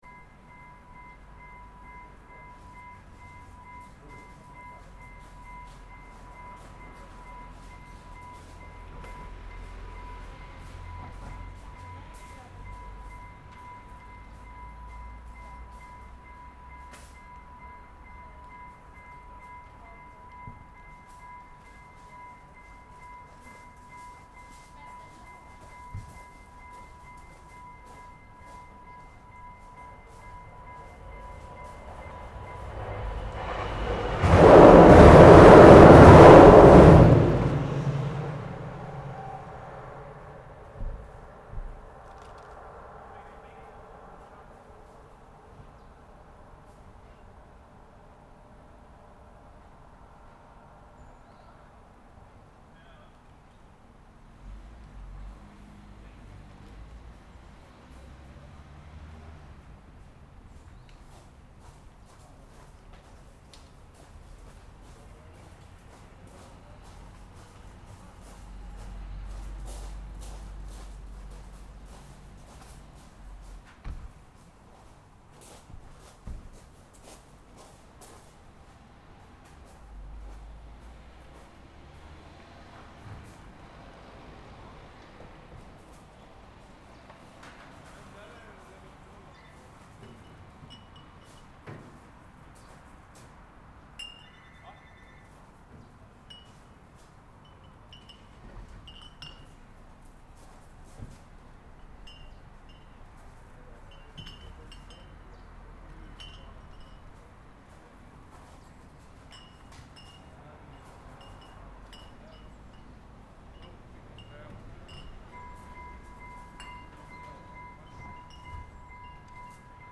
Göta Canal (lock): Soundscape
This is the soundscape of the environment around the lock at the Canal. It is usually a very quiet and tranquil town (apart from the train), and this is how the environment can sound during a normal day in the summer.